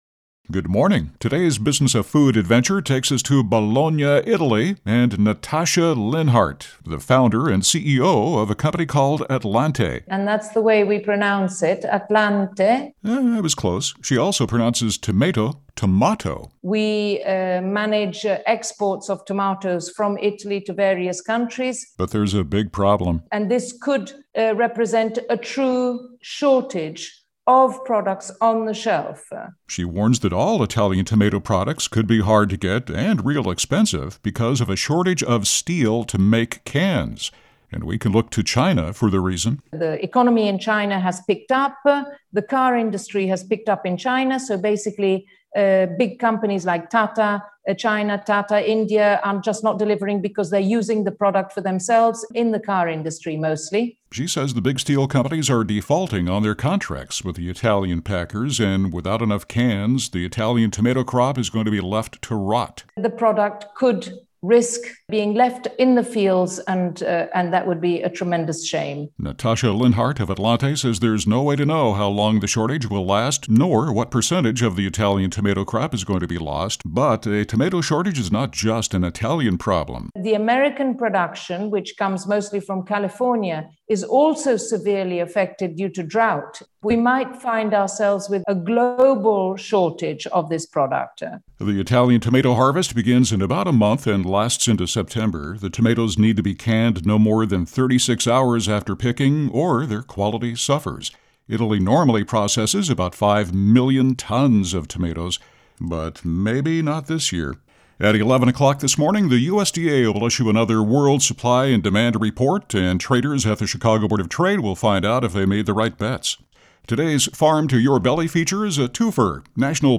Crisis of tomatoes. Interview for WGN Radio 720